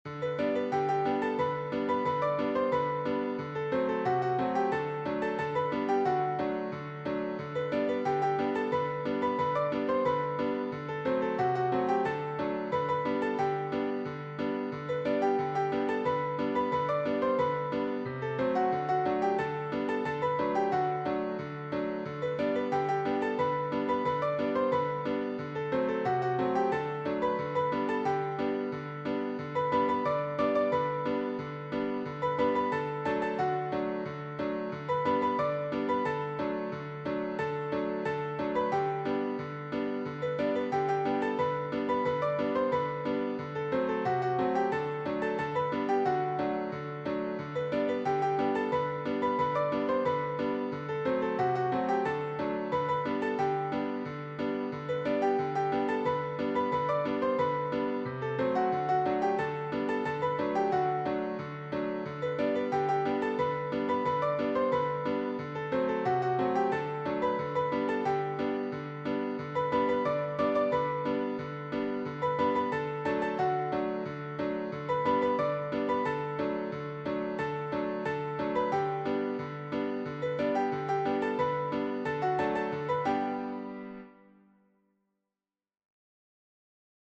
Joyous
Classic ragtime
Piano only